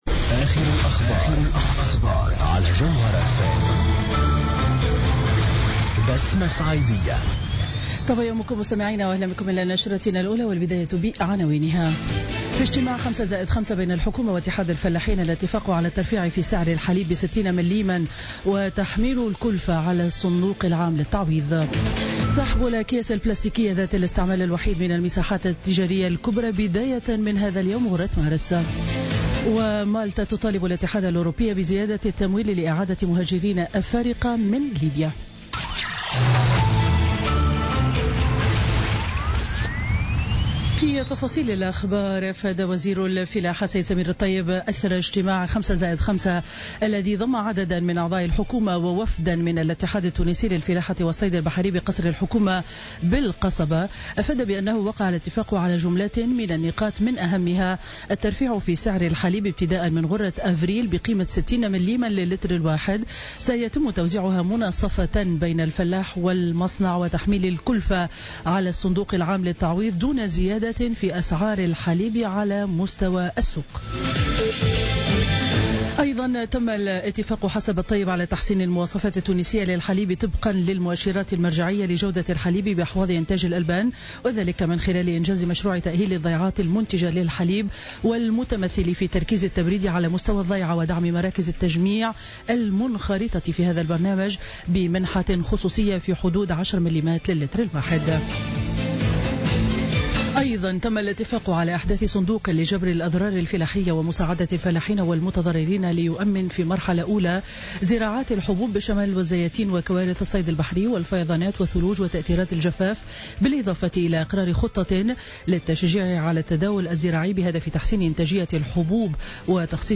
نشرة أخبار السابعة صباحا ليوم الأربعاء 1 مارس 2017